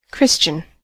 Ääntäminen
Synonyymit kind (arkikielessä) sweet helpful neighbourly neighborly charitable Ääntäminen US Tuntematon aksentti: IPA : /ˈkɹɪʃtʃən/ IPA : /ˈkɹɪstjən/ IPA : /ˈkɹɪstʃən/ Lyhenteet ja supistumat Xian xian Xtian